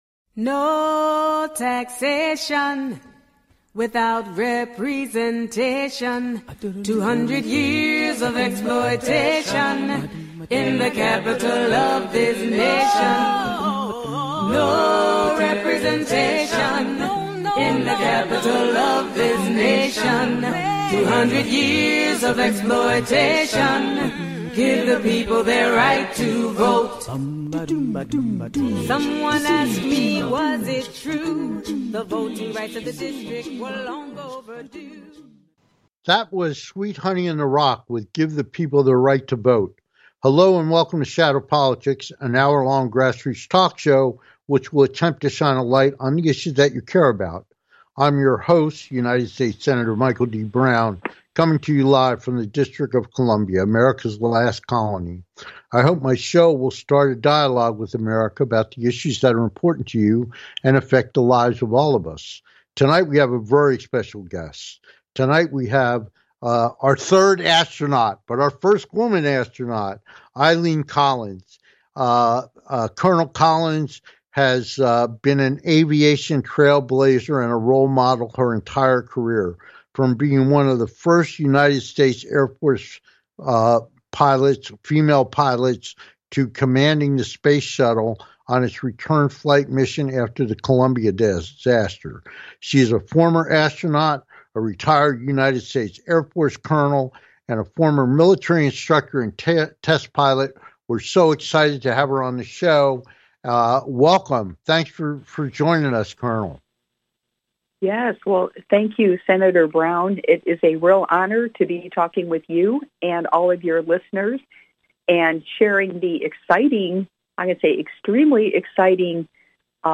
Fly Me to the Moon - With Guest Astronaut, Educator and Consultant – Eileen M. Collins